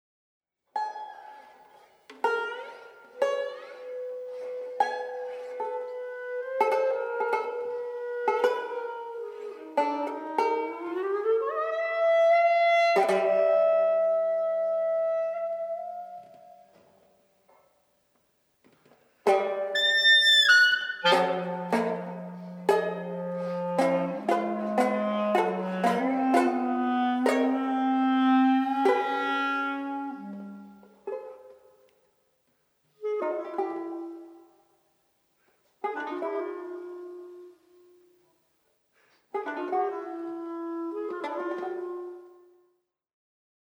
Banjo
Klarinette
Juni 2002, Wien